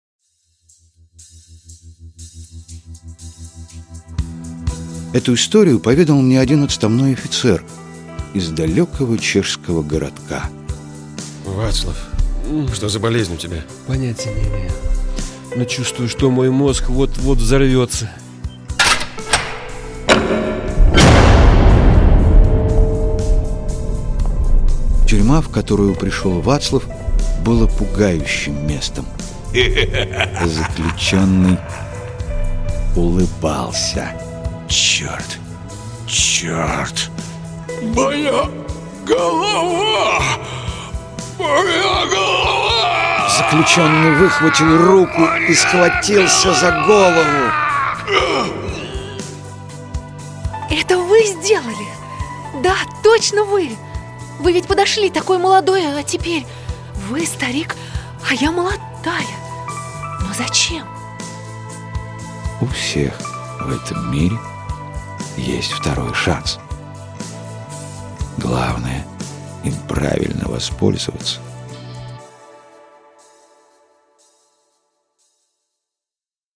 Аудиокнига Часовщик. Рассказ «История второго шанса» | Библиотека аудиокниг